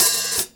hat 5.wav